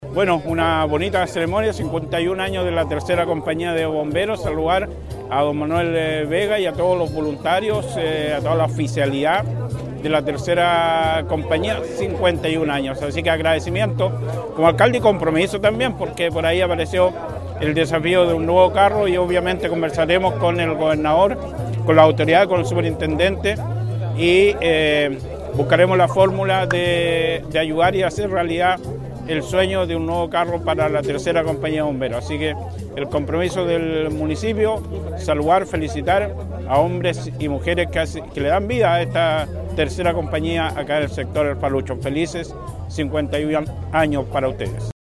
Tercera Compañía de Bomberos de Constitución celebró su 51° aniversario con emotiva ceremonia en El Falucho.
Carlos Valenzuela – Alcalde de Constitución
Carlos-Valenzuela-Alcalde-de-Constitucion-2.mp3